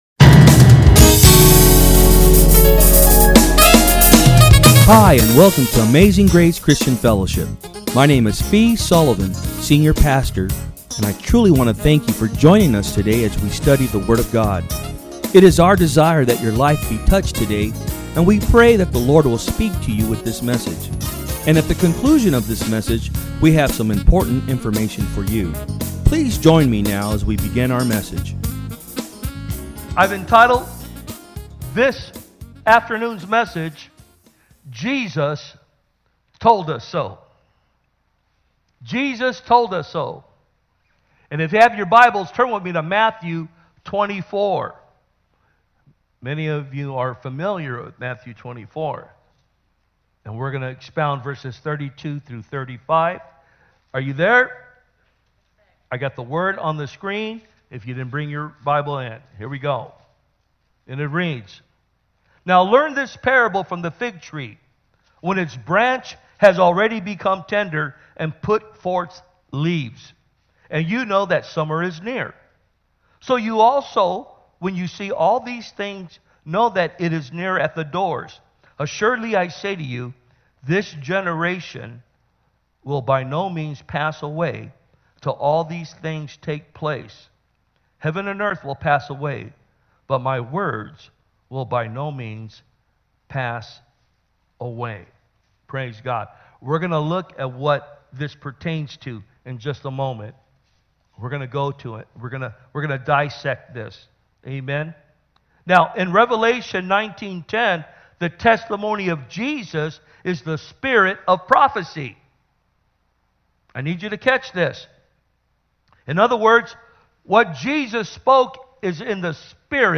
Message
From Service: "Sunday Am"